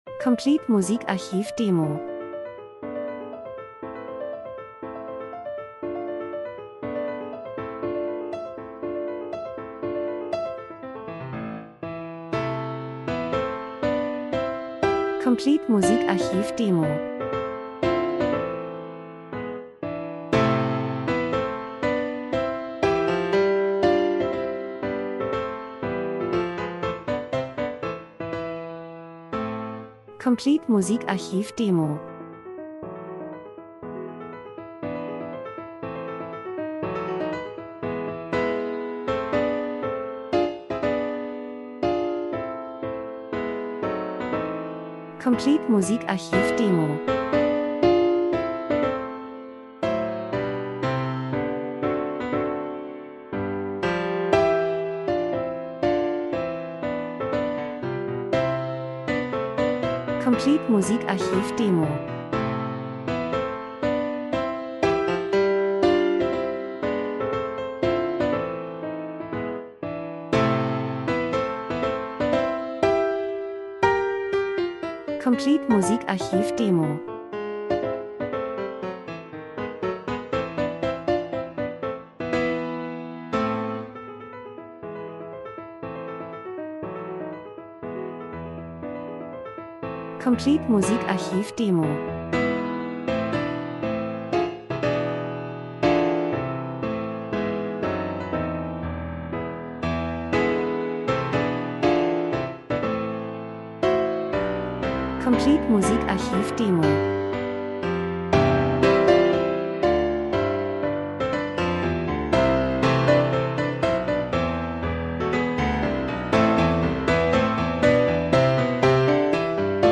Landschaften Natur fliessendes Wasser Wetter